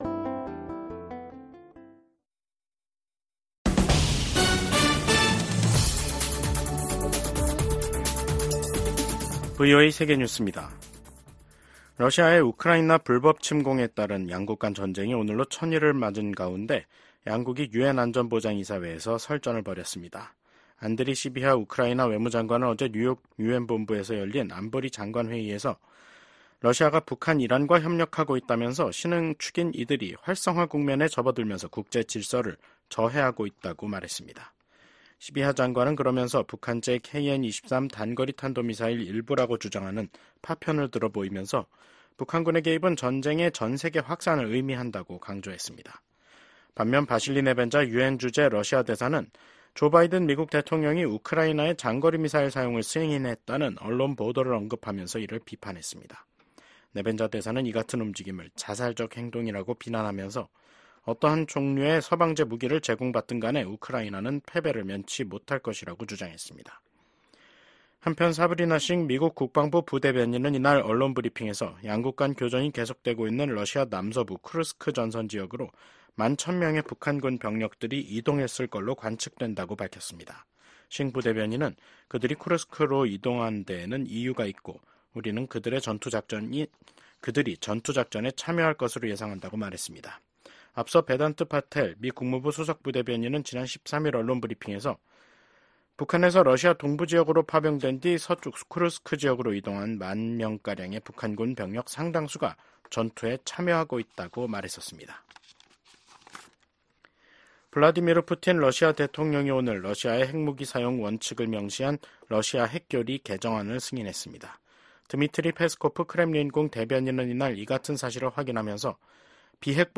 VOA 한국어 간판 뉴스 프로그램 '뉴스 투데이', 2024년 11월 19일 2부 방송입니다. 미국은 러시아가 북한군을 우크라이나 전쟁에 투입해 분쟁을 고조시키고 있다며, 북한군의 추가 파병을 차단하기 위해 중국과 직접 소통하고 있다면서, 단호한 대응 의지를 확인했습니다. 우크라이나 전쟁 발발 1천일을 맞아 열린 유엔 안보리 회의에서 북한군의 러시아 파병과 두 나라 간 군사 협력에 대한 강한 비판이 쏟아졌습니다.